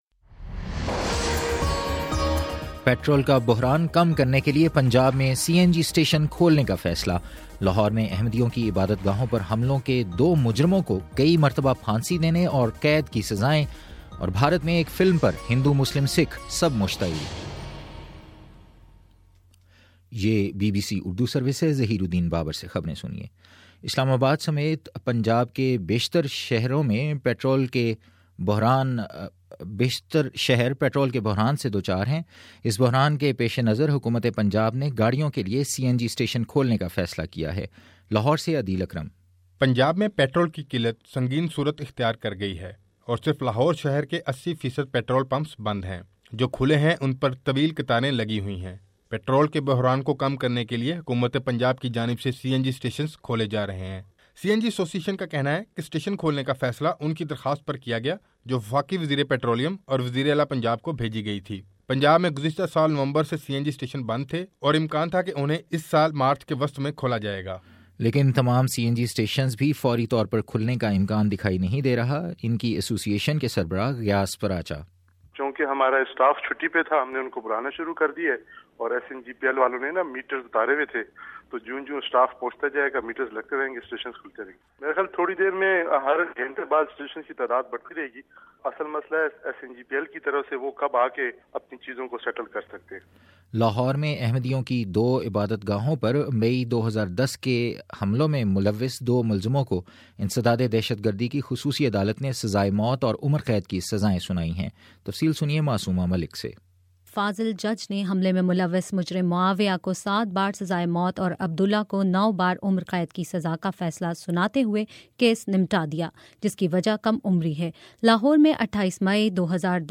جنوری 17: شام چھ بجے کا نیوز بُلیٹن